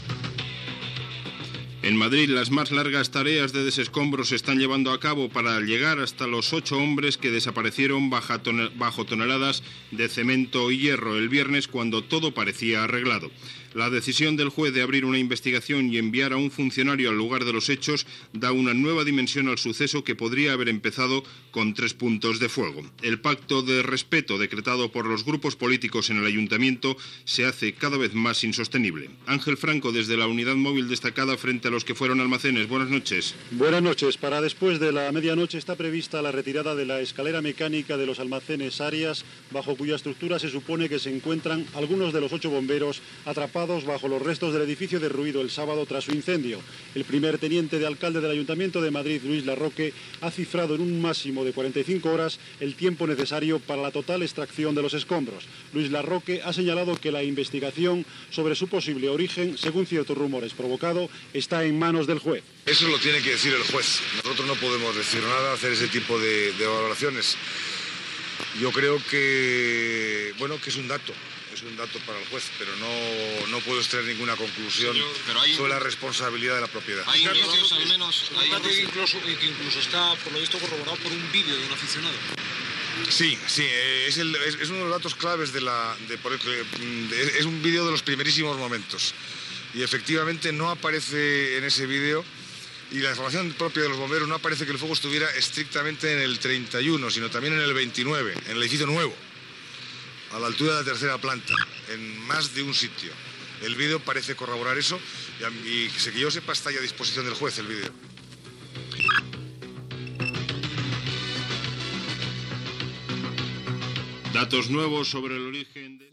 Connexió amb la unitat mòbil que està davant dels Almacenes Arias de Madrid que s'havien creat el dia 4 de setembre de 1987.
Informatiu